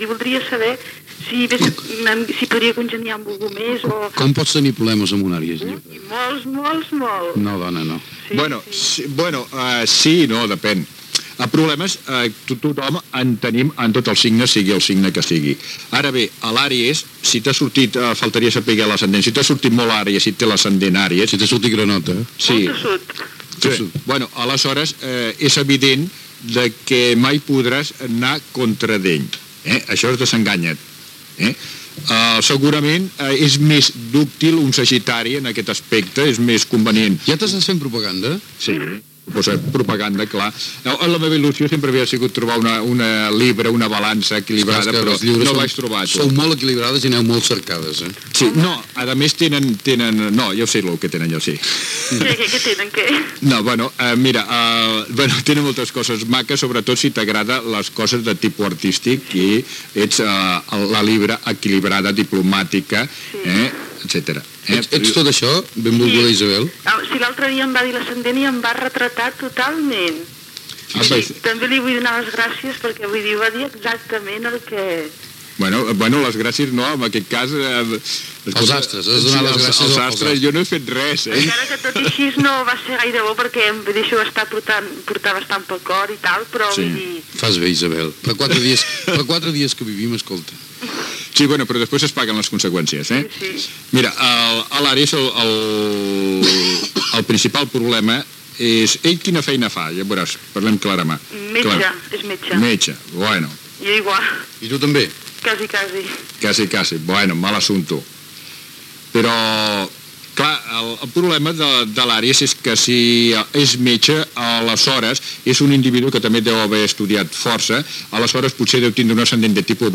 Trucades d'oïdores